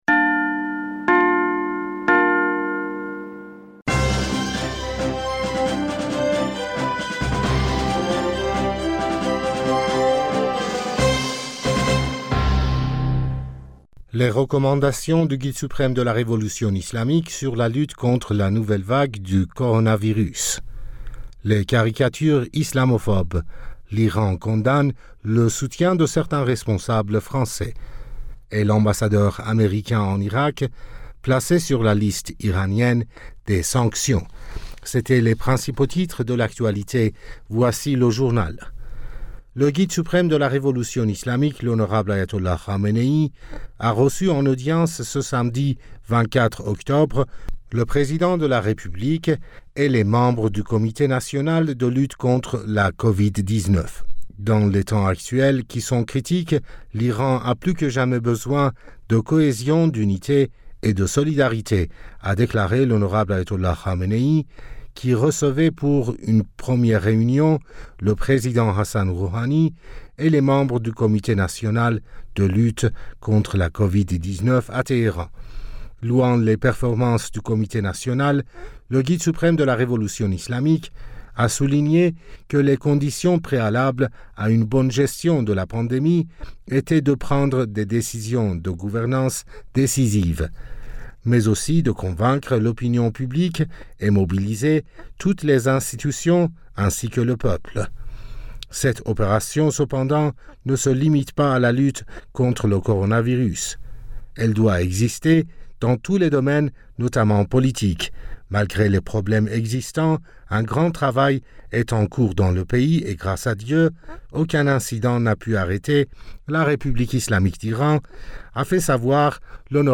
Bulletin d'informationd u 24 Octobre 2020